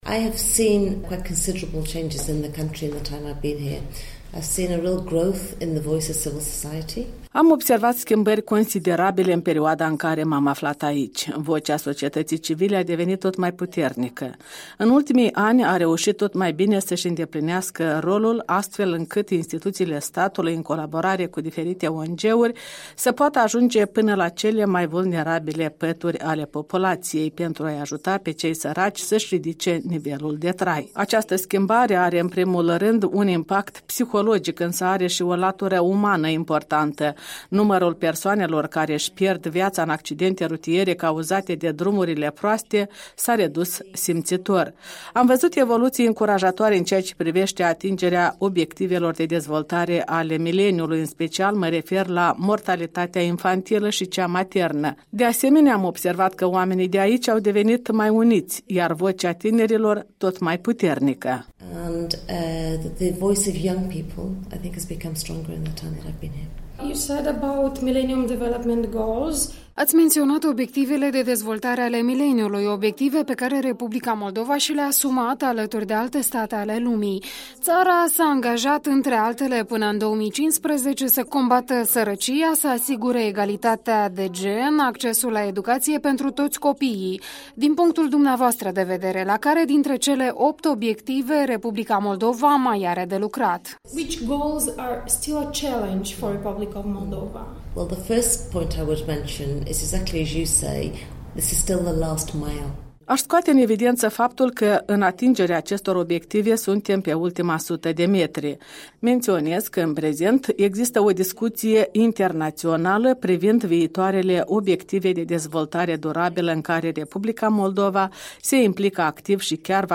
Interviul dimineții: Nicola Harrington-Buhay, coordonatoare rezidentă ONU la Chișinău